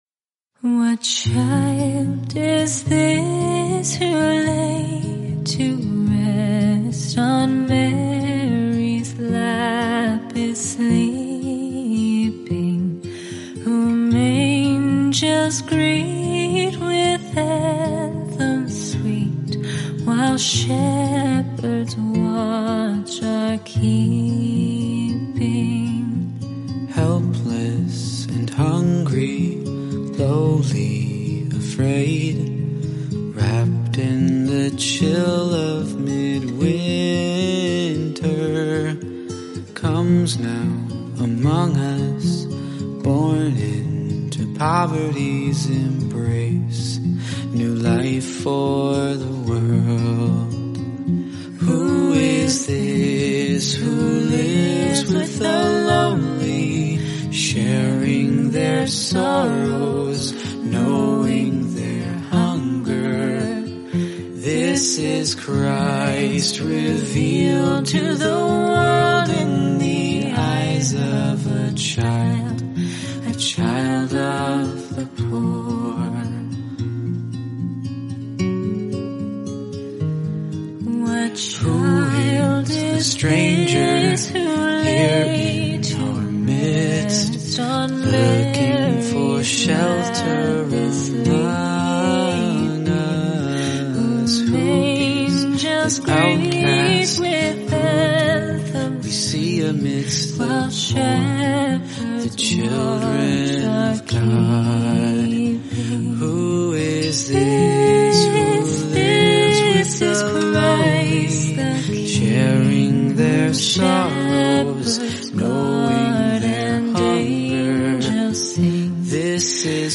is sung in a round with two voices.